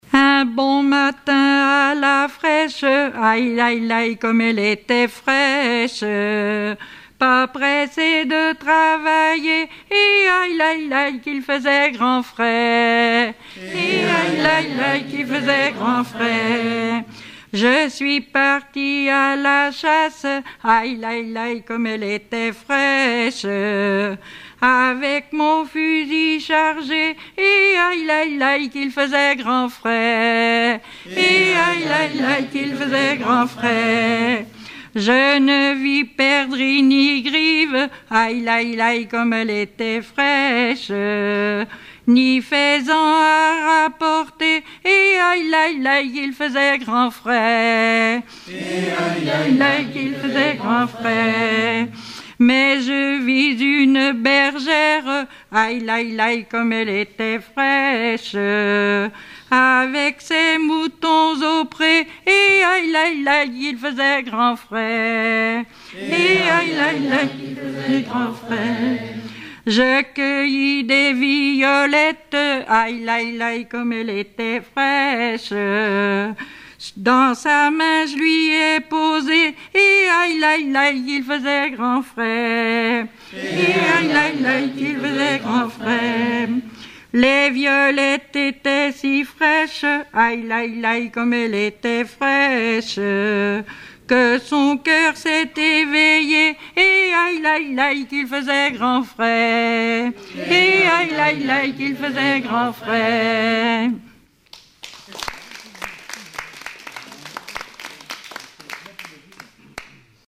Genre laisse
Chansons traditionnelles et populaires
Pièce musicale inédite